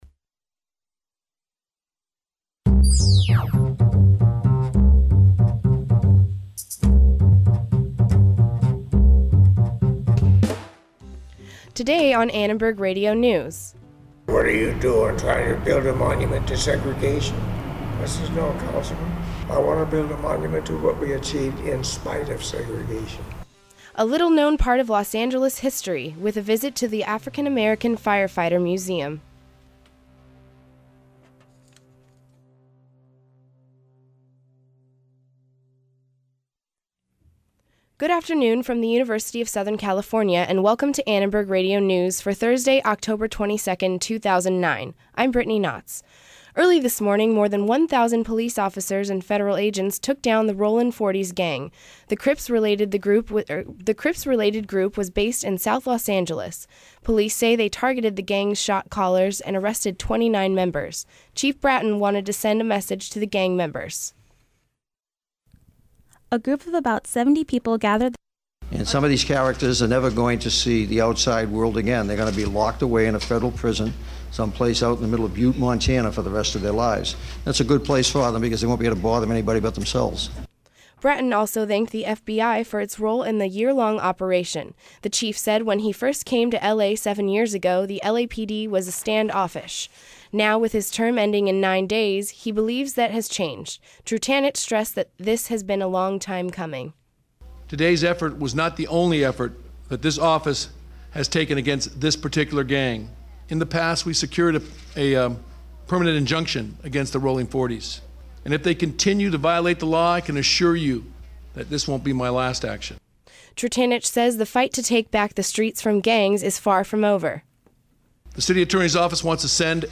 Hear what local law enforcement has to say about the raid. Also, listen to the reaction of South L.A. residents about gang violence and prevention.